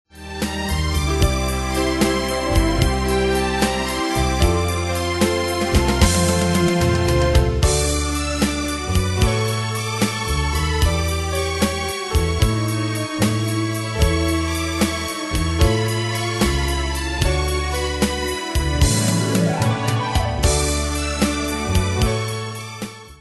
Style: PopFranco Ane/Year: 1982 Tempo: 75 Durée/Time: 2.35
Danse/Dance: Ballade Cat Id.
Pro Backing Tracks